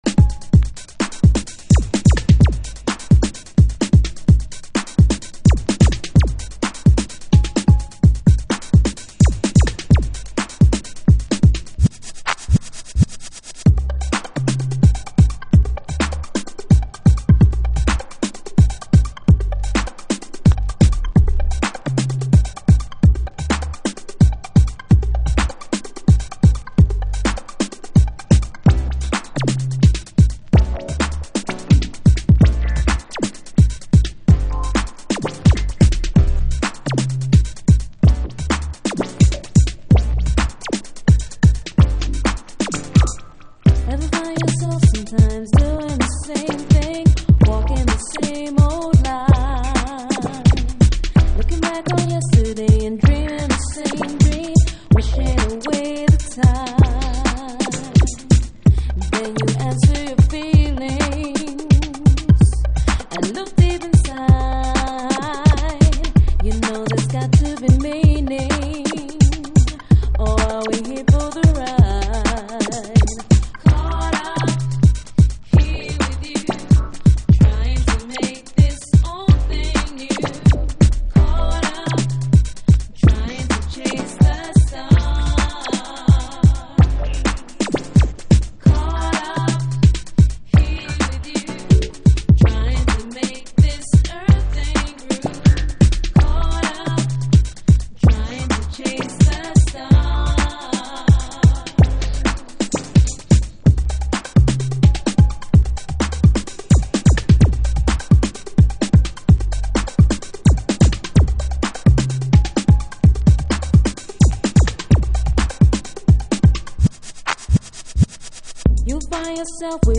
複雑に刻まれサンプリングされたビートとアコースティック群が織りなす新機軸のグルーヴ。